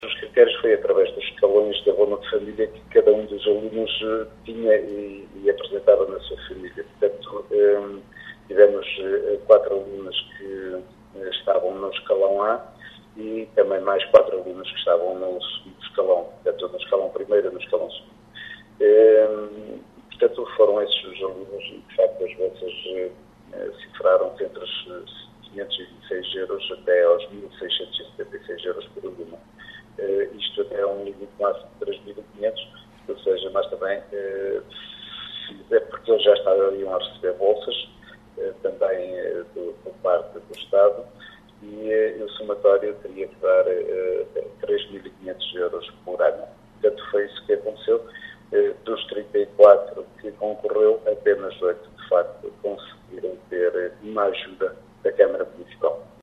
O presidente da câmara explica como foram distribuídos os 10 mil euros que dotavam esta medida.